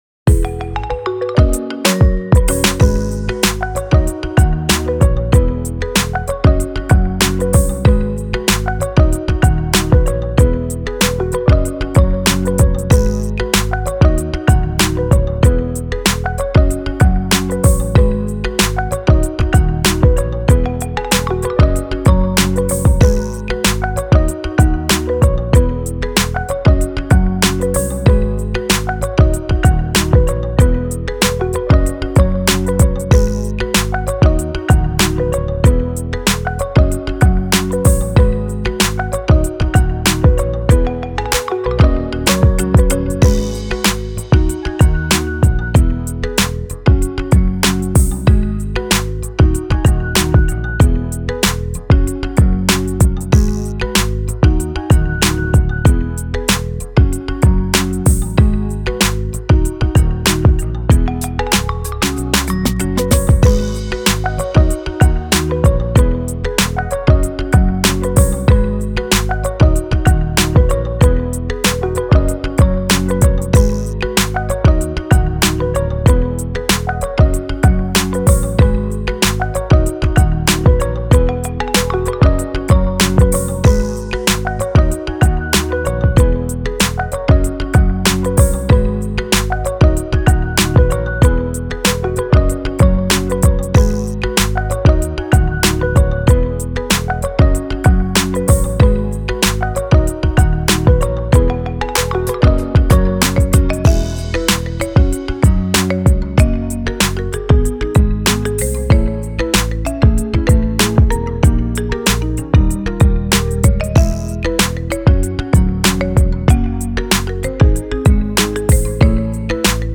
フリーBGM
明るい・ポップ